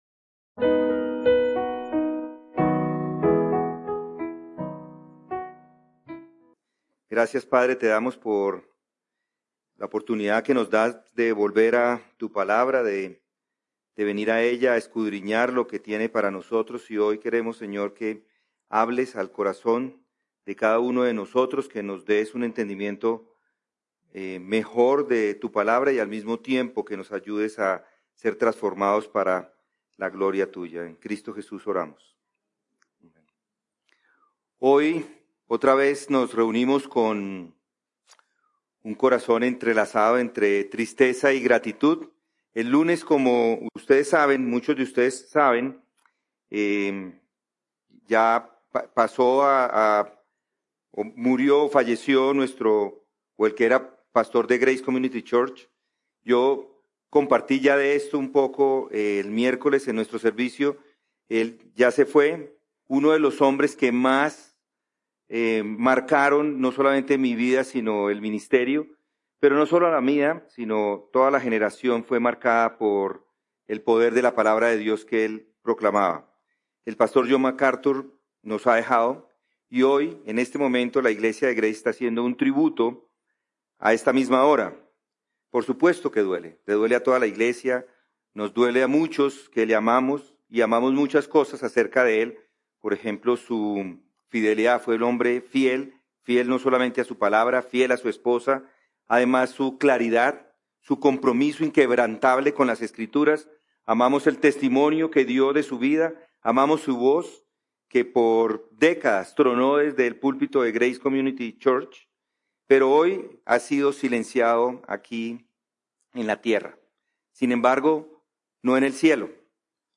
Sermones | Comunidad de Gracia